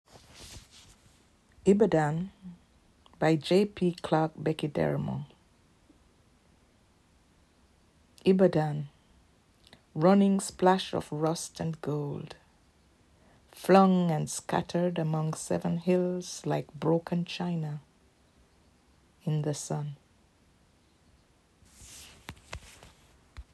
Ibadan by J P Clark Bekederemo read by Valerie Bloom
Ibadan-by-J-P-Clark-Bekederemo-read-by-Valerie-Bloom.m4a